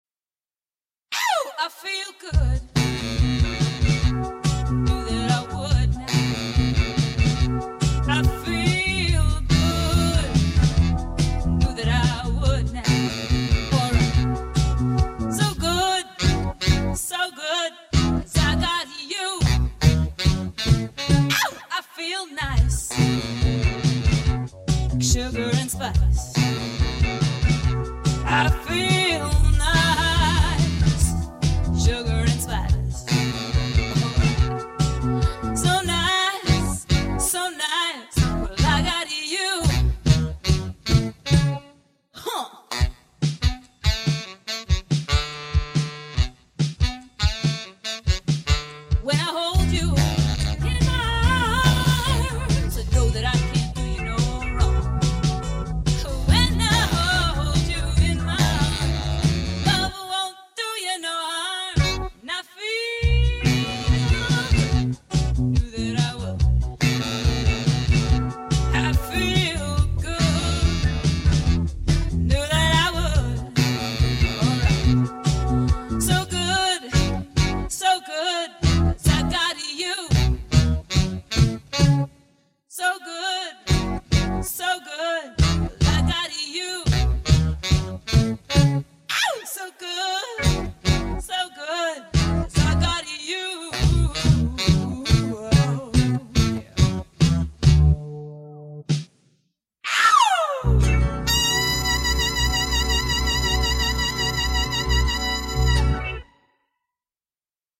This CD was recorded at TallMan Studios in 1988.
organ
saxaphone
guitar
drums